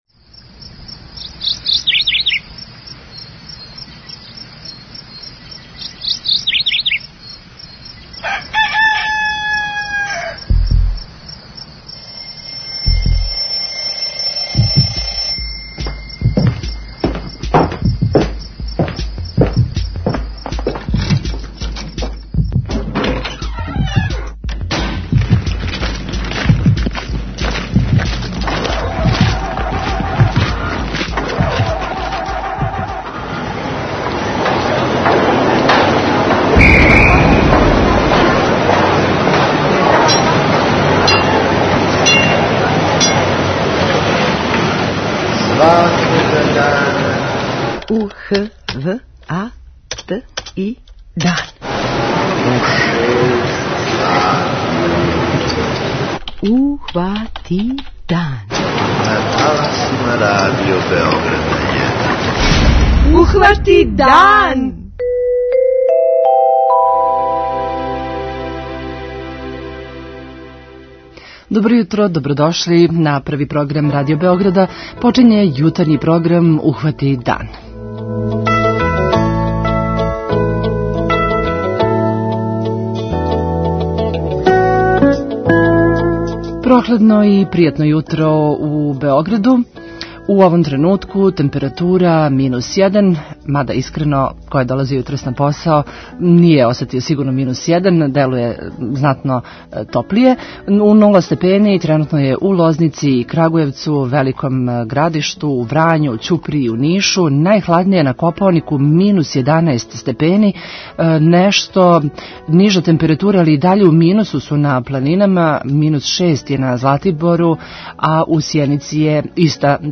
Na Dan radija, Van Gog objavljuje svoj 10 album More bez obala. O pesmama, vezi sa radijom, koncertima i simbolici u muzici govori Zvonimir Đukić Đule.